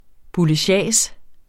Udtale [ buləˈɕæˀs ]